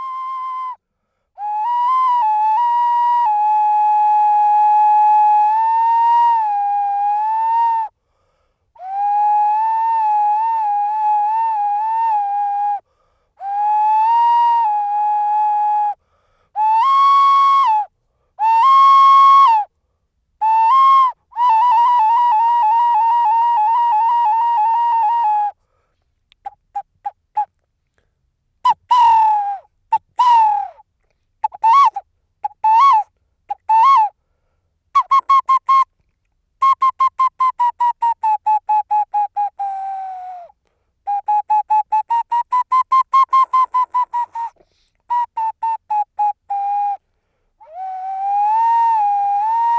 Audio 5. Silbato de cerámica en forma de pareja de mujer y anciano, contexto ritual del Patio 1, Complejo Oeste del Grupo B (fig. 7f).